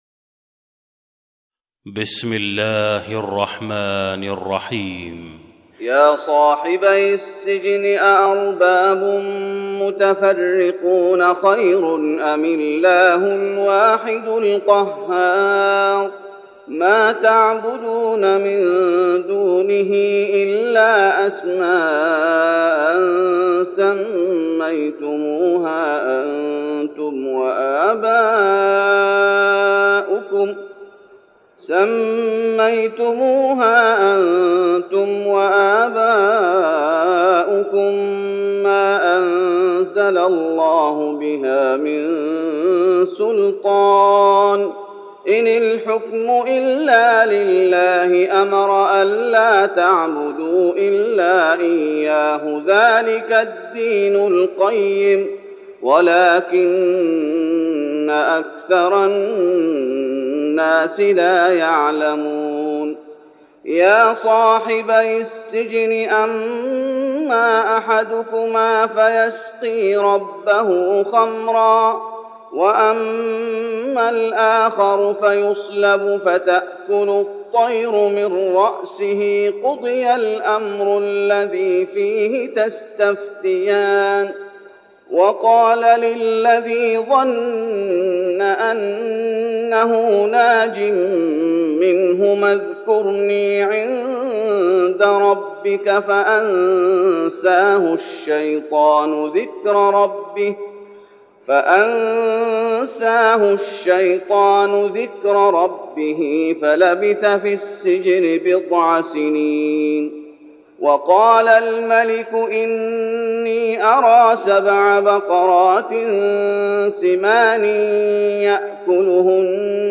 تهجد رمضان 1412هـ من سورة يوسف (39-104) Tahajjud Ramadan 1412H from Surah Yusuf > تراويح الشيخ محمد أيوب بالنبوي 1412 🕌 > التراويح - تلاوات الحرمين